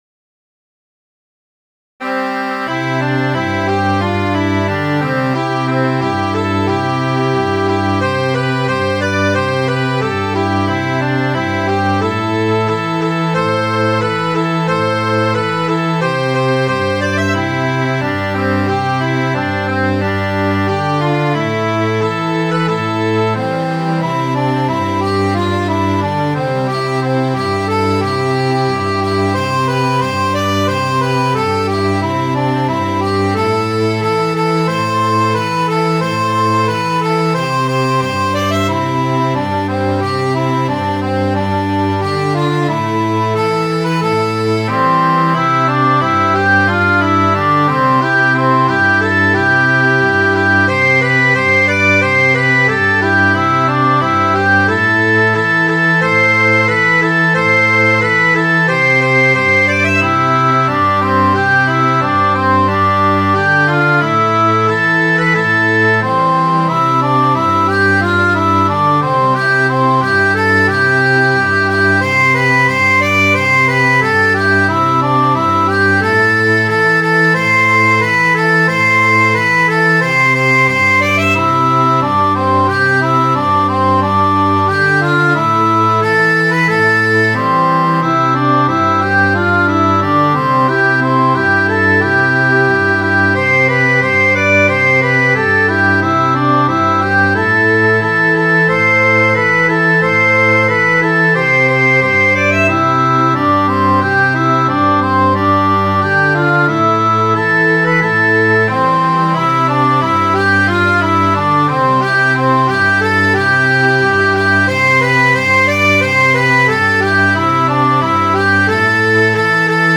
Midi File, Lyrics and Information to The Trees They Do Grow High (Daily Growing)